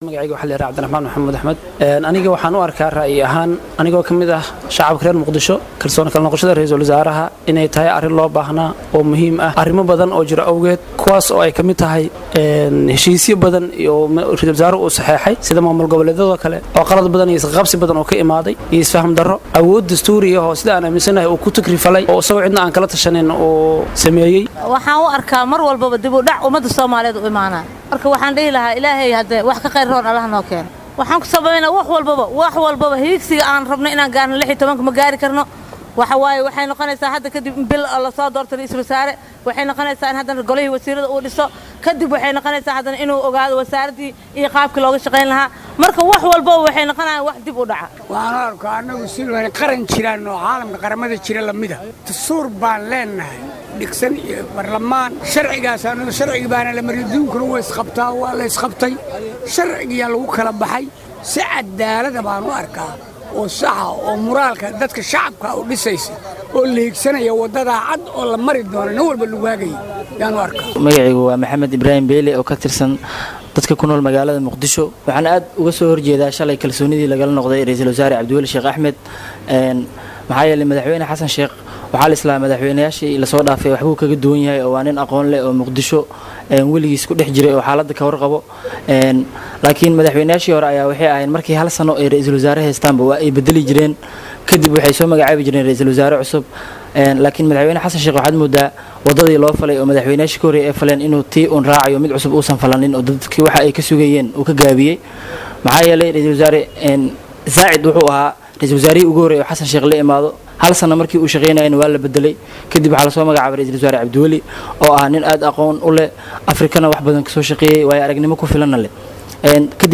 Sidoo kale, haweeney ka mid ah shacabka Muqdisho ayaa iyaduna sheegtay inay u aragto tallaabadan dib u dhac ku tahay shacabka Soomaaliyeed, taas oo ay sabab uga dhigtay inay noqonayso in ra’iisul wasaare lasoo dhiso, inuu xukuumad soo dhiso, in wasiirraddu ay shaqada la qabsadaan, iyadoo xustay in wax walba ay dib u dhacayaan sida doorasho dalka la gaarsiiyo 2016-ka.
DHAGEYSO: Ra'yiga Shacabka Muqdisho ay ka dhiibteen Go'aankii Baarlamaanka
Oday isaguna ka mid ah shacabka Muqdisho ayaa kalsooni kala laabashadii xukuumadda Soomaaliya ku tilmaamay mid muujinaysa in Soomaaliya ay ka mid tahay qaramada jira oo arrimahooda xallisan kara, isagoo xusay in lagu kala baxay sharciga, kaddib markii la is-qabtay, wuxuuna ku tilmaamay inay tahay tallaabadan mid cadaalad ah oo shacabka niyadda u dhisaysa.
Ugu dambeyn, haweeney iyaduna ka mid ah shacabka Muqdisho ayaa hadal ay siisay warbaahinta ku sheegtay inay soo dhaweynayso kalsooni ra’iisul wasaaraha lagala laabtay, iyadoo xustay in la rido xukuumad walba oo aan bulshada waxba kusoo kordhinayn.